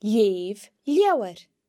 The slender L sound is made by pressing your tongue against the roof of your mouth (palate) while pronouncing the letter, and occurs when the L is before or after an e or i. The slender L can be heard in leugh (read):